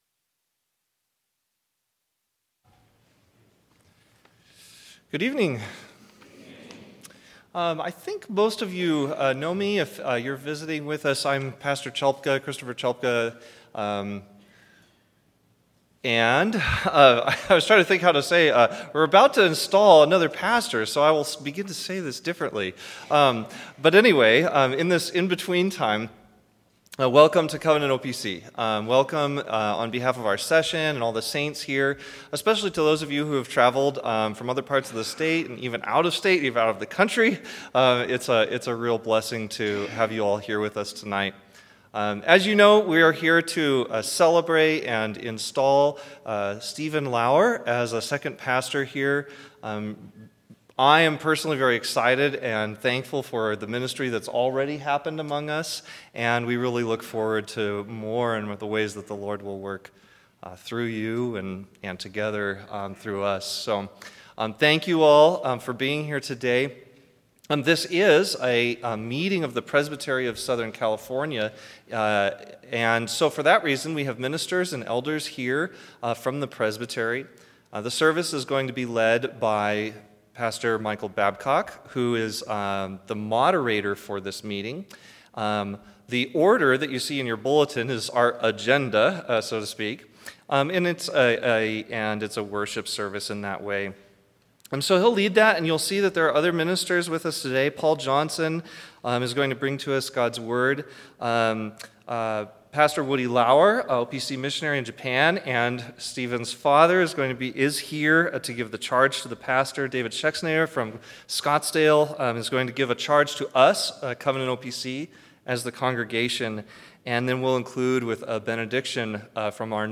Installation Service